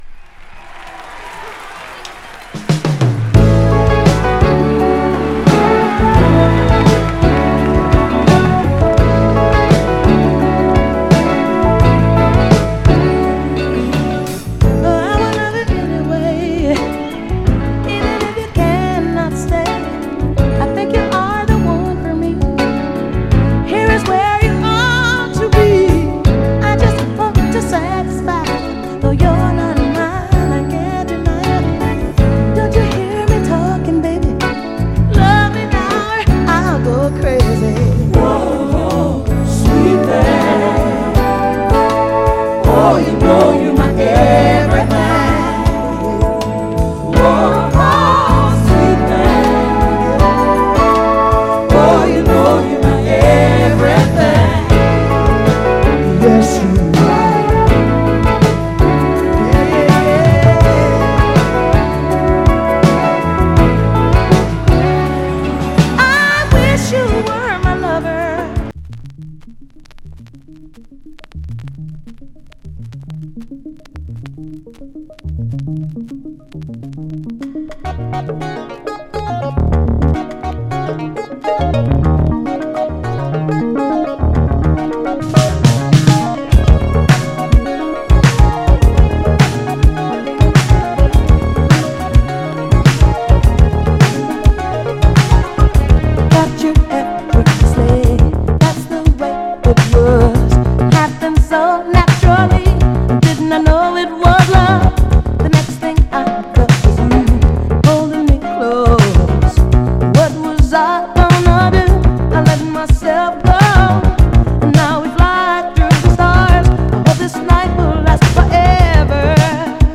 とても静かなA面イントロで少しチリつきますが、全体的にはグロスが残っておりプレイ概ね良好です。
※試聴音源は実際にお送りする商品から録音したものです※